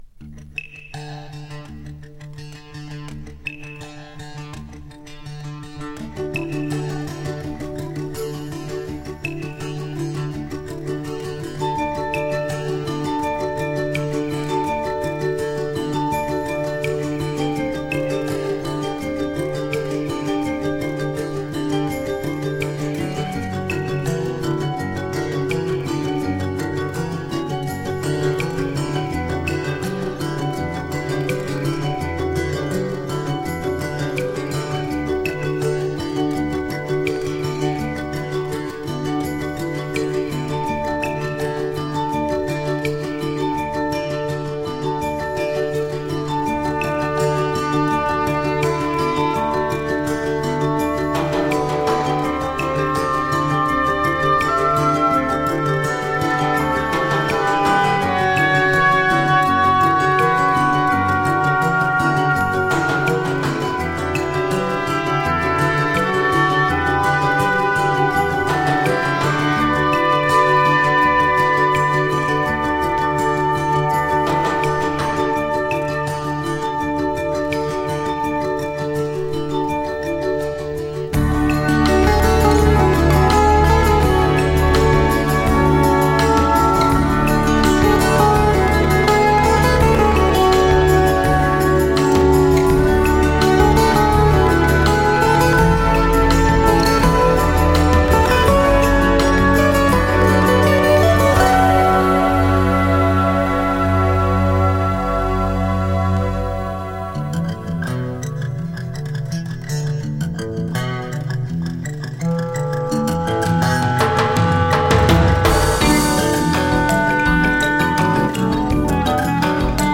German New Age Fusion!
【NEW AGE】【FUSION】&lt
ドイツ出身のギタリスト、マルチプレイヤー。